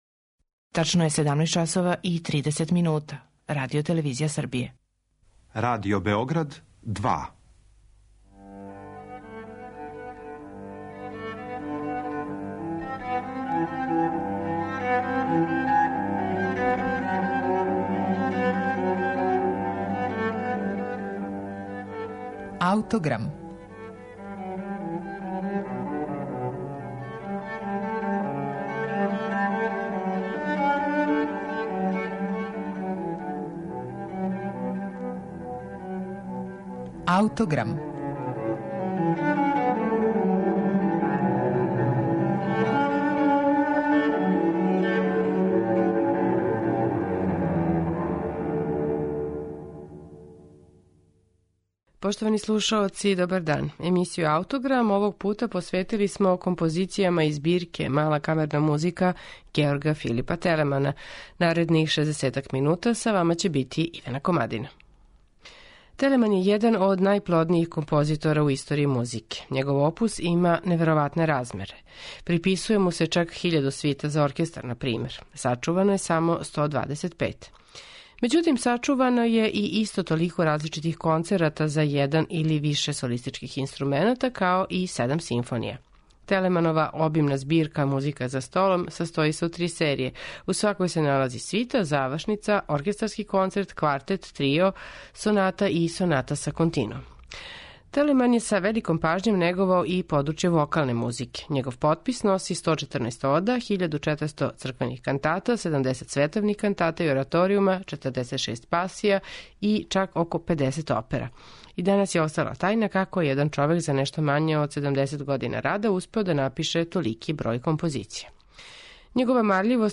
на оригиналним барокним инструментима